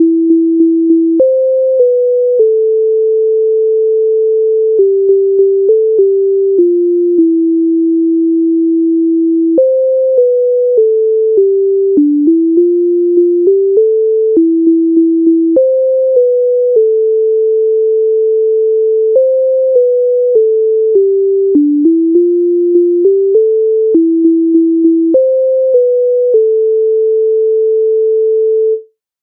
MIDI файл завантажено в тональності a-moll
Ой на горі два дубки Українська народна пісня
Ukrainska_narodna_pisnia_Oj_na_hori_dva_dubky.mp3